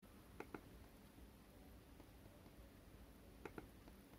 クリック音は非常に静か、
スマートフォンを、マウスから3cm程度で記録した音です。
The click sound is very quiet,
This is the sound recorded from a smartphone about 3 cm from the mouse